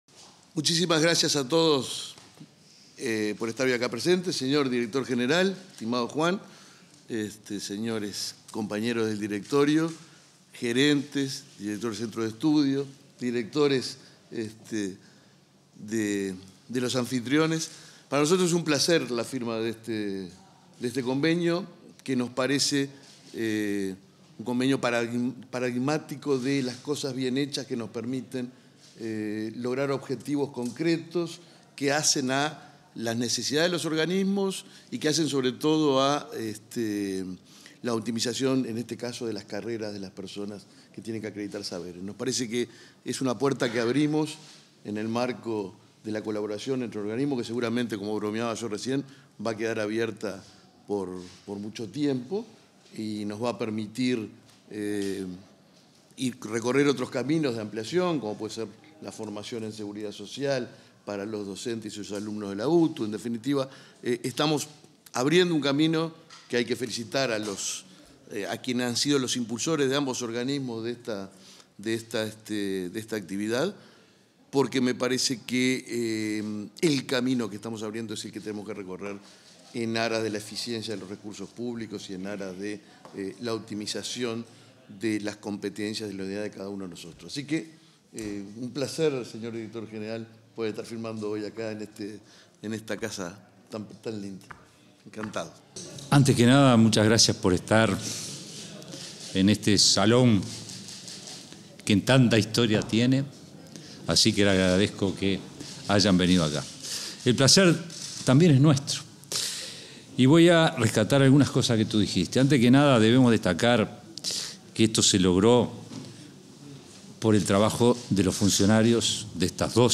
Firma de un convenio entre el BPS y la DGETP-UTU 29/08/2024 Compartir Facebook X Copiar enlace WhatsApp LinkedIn En el marco de la firma de un convenio para la acreditación de saberes, capacitaciones profesionales y colaboración, este 29 de agosto, se expresaron el presidente del Banco de Previsión Social (BPS), Alfredo Cabrera, y el director general de Educación Técnico Profesional, Juan Pereyra.